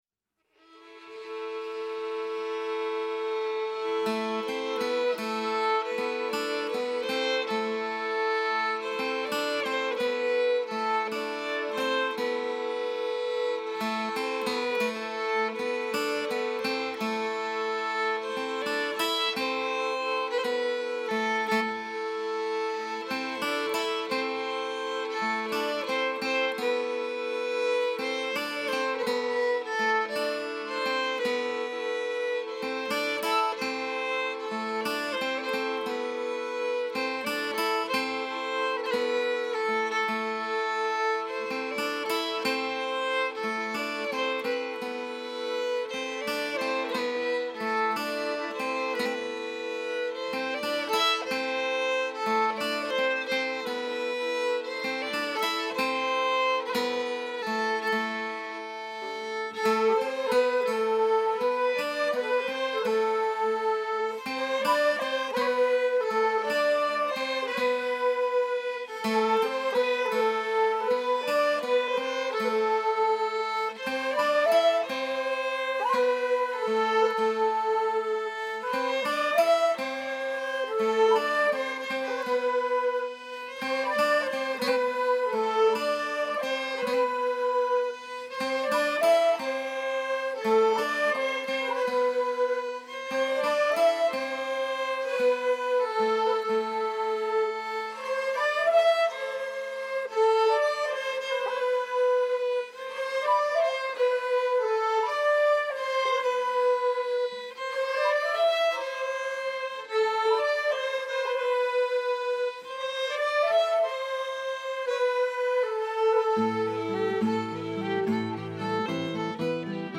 Les enregistrement de groupes d’élèves ont été effectués à la Carène, en avril 2016.
En tu mañ, l’atelier adulte de Landerneau - Sizun joue une mélodie et enchaîne avec une gavotte.